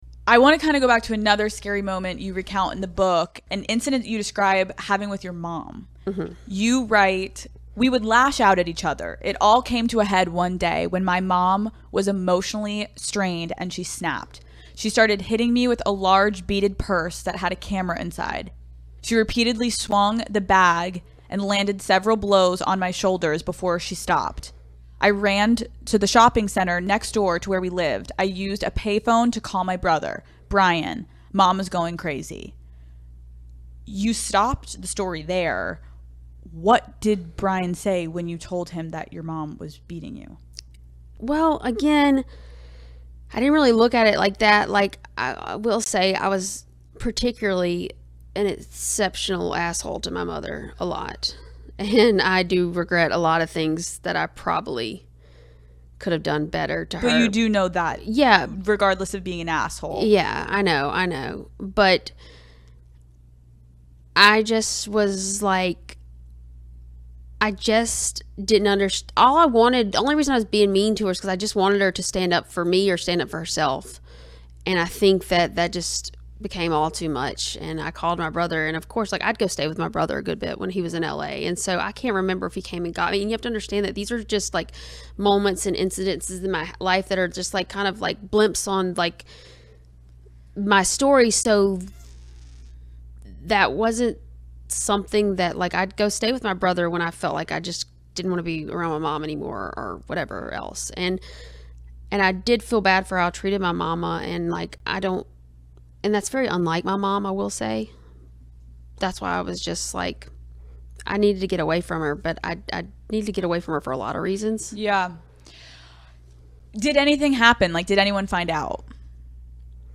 Jamie Lynn Spears Breaks Down in Latest Bombshell Interview: 'My Whole Life I Kind of Felt Like I Didn't Matter'
Jamie Lynn Spears has once again spoken out about her relationship with her sister Britney Spears, breaking down in tears as she detailed growing up alongside the pop star.